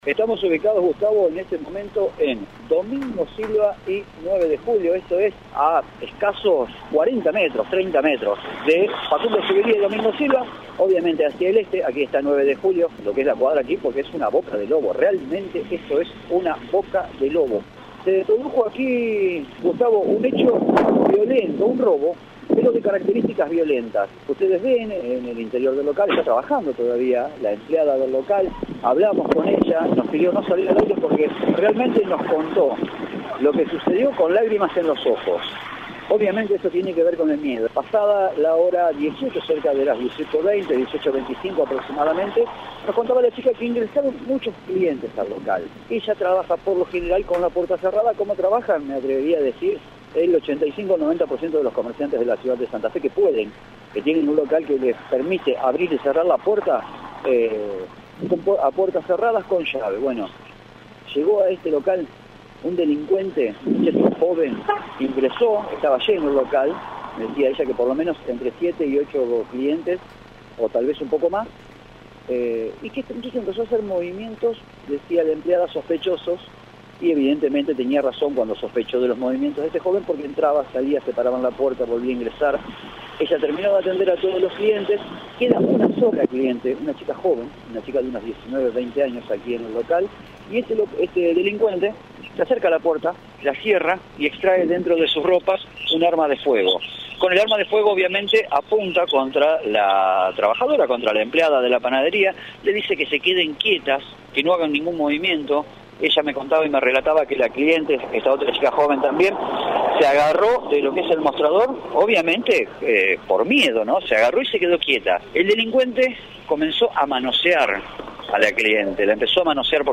Muy angustiada y en medio del llanto, la entrevistada detalló que el hombre abusó de la clienta, tocándola inapropiadamente durante varios minutos, mientras continuaba con sus amenazas.
El móvil desde la panadería asaltada: